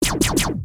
EnemyLasers.wav